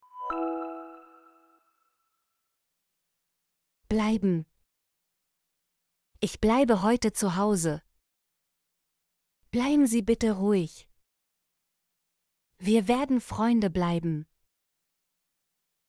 bleiben - Example Sentences & Pronunciation, German Frequency List